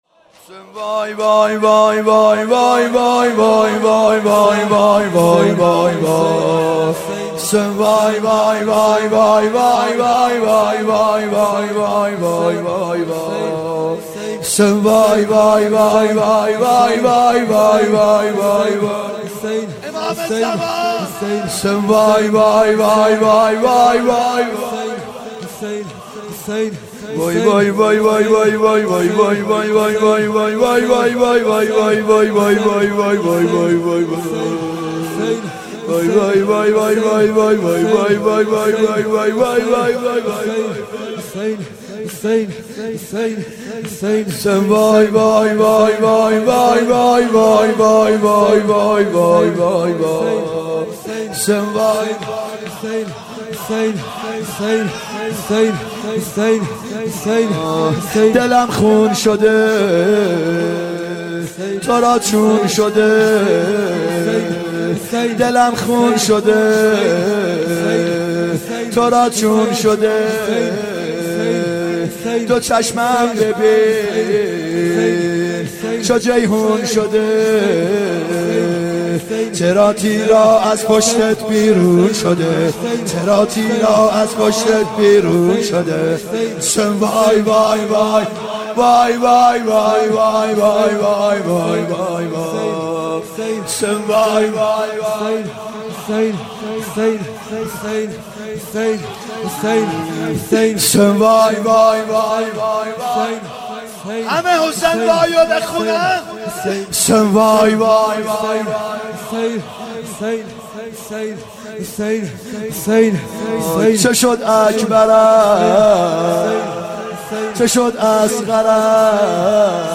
محرم 91 شب دهم شور
محرم 91 ( هیأت یامهدی عج)